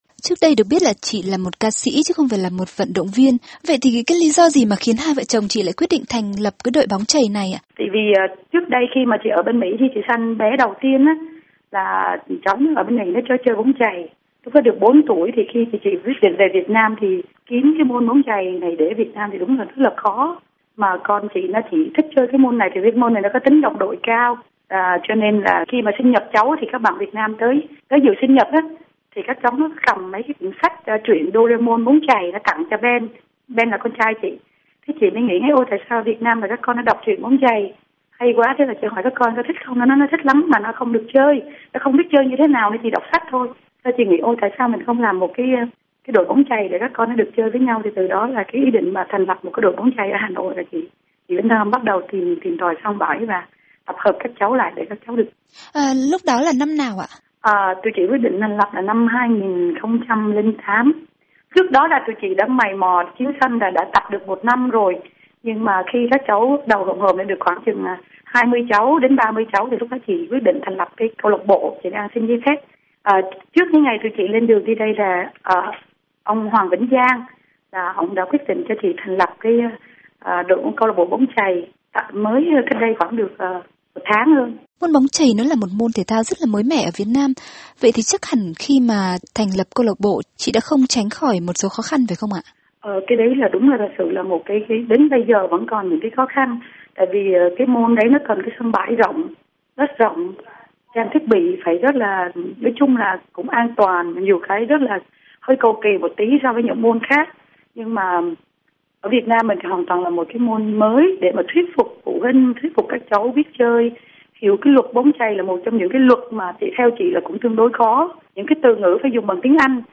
Cuộc phỏng vấn